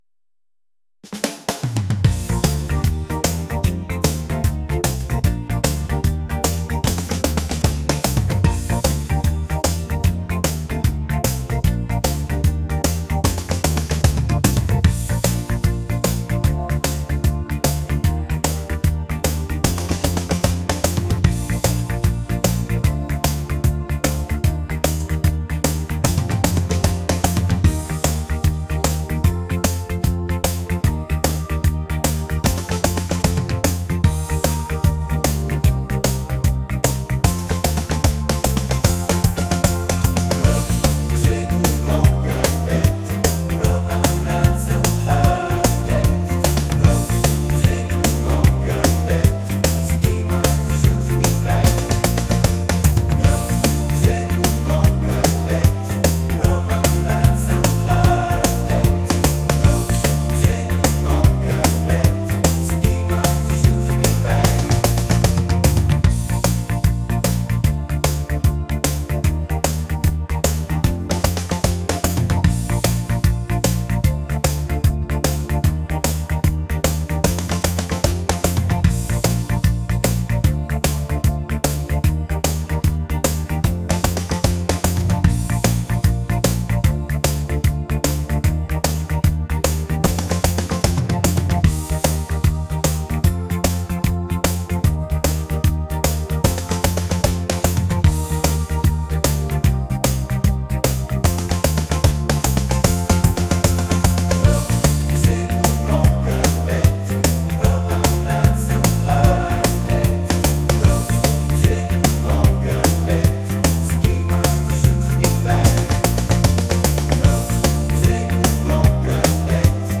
明るい